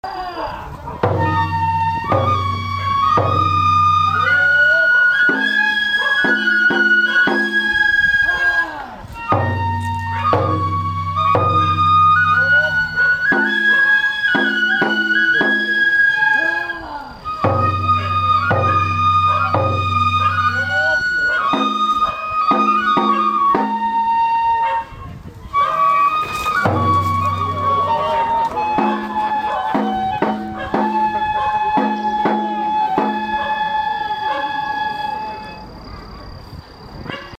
戸毎舞「おかざき」